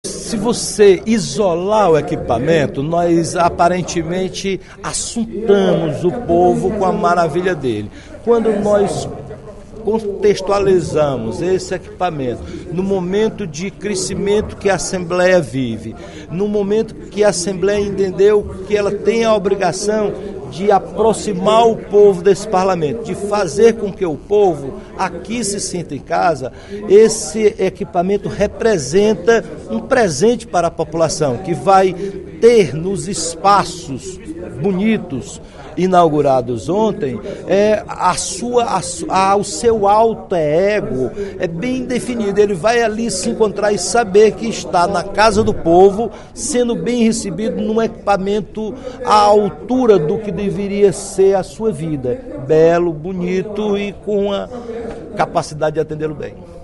O deputado Roberto Mesquita (PV) comentou, na sessão plenária desta sexta-feira (16/03) da Assembleia Legislativa, a inauguração do Edifício Deputado José Euclides Ferreira Gomes da Casa, ocorrida ontem à noite.